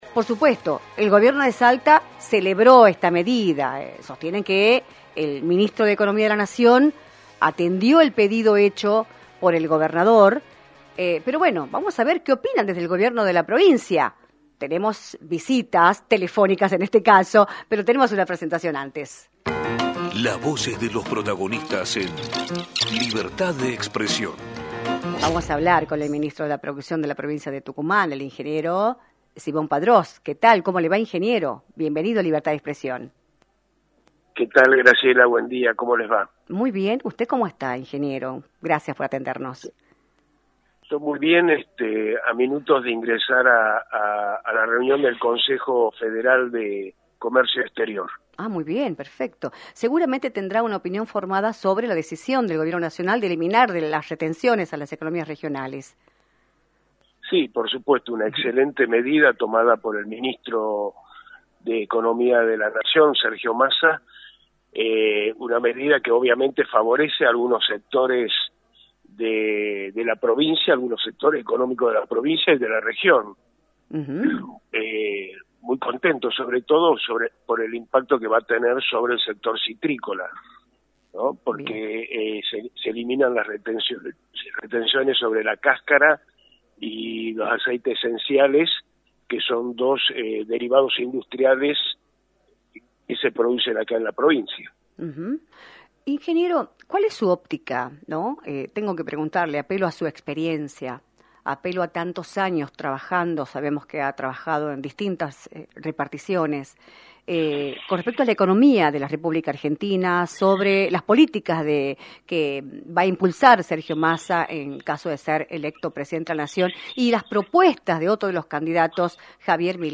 Álvaro Simón Padrós, Ministro de Desarrollo Productivo, analizó en “Libertad de Expresión”, por la 106.9, las repercusiones de la decisión del Gobierno de eliminar las retenciones a las economías regionales.